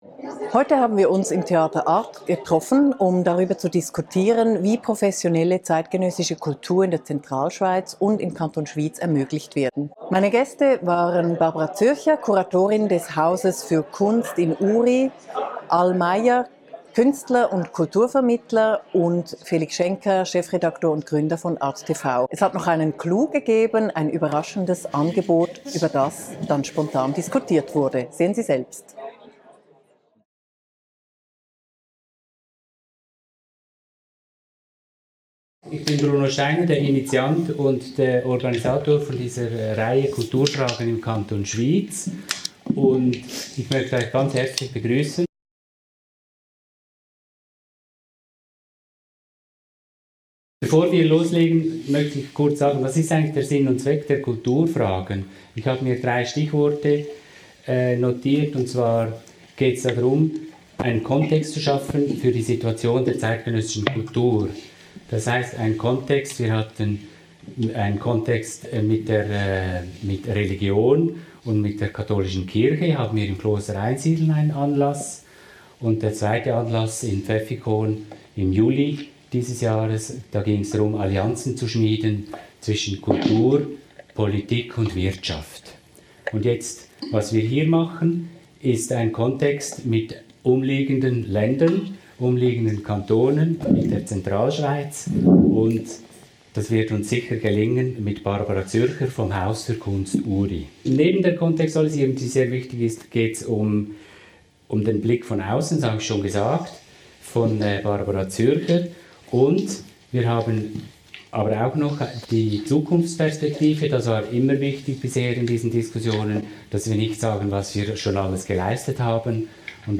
Kulturfragen #3 fang am Sonntag, 18 September 2022 im Theater Arth statt